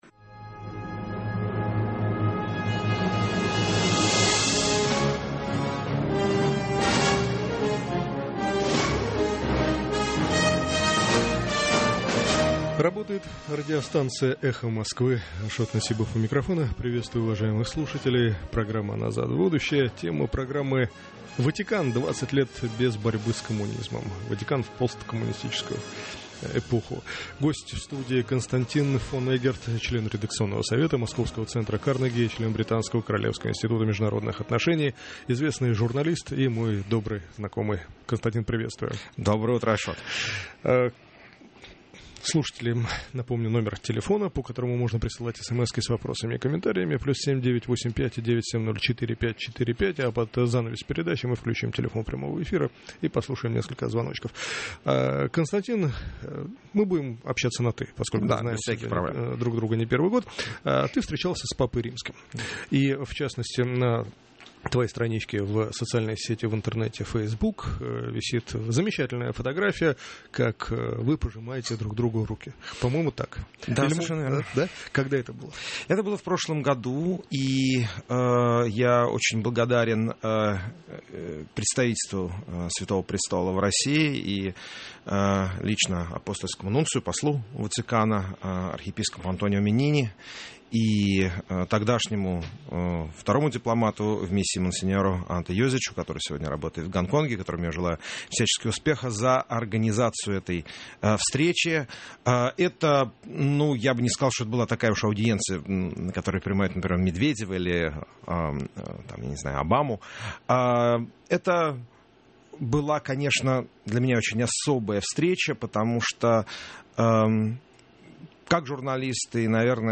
Гость в студии: Константин фон Эггерт, член редакционного совета Московского Центра Карнеги, член Британского Королевского Института международных отношений, известный журналист и мой добрый знакомый.